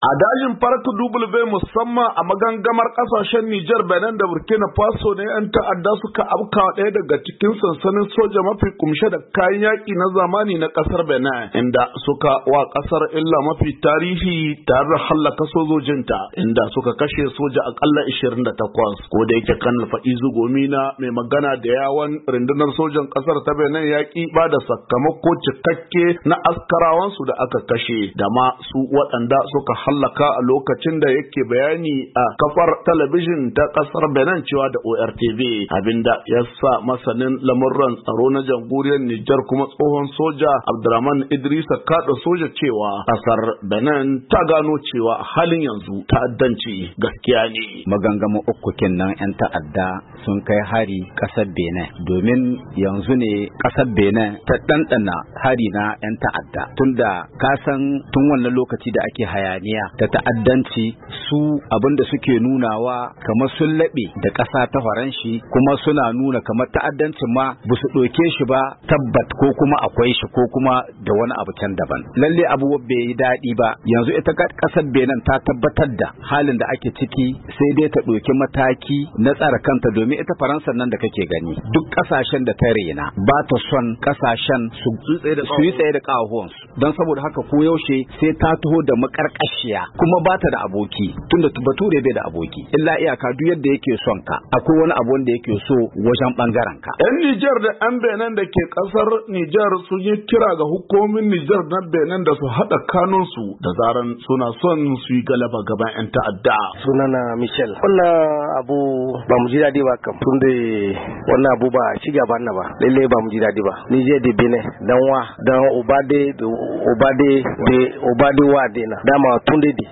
Saurai cikakken rahoto